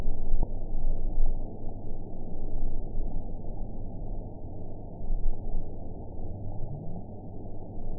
event 918216 date 08/18/23 time 10:31:15 GMT (1 year, 10 months ago) score 8.09 location TSS-AB09 detected by nrw target species NRW annotations +NRW Spectrogram: Frequency (kHz) vs. Time (s) audio not available .wav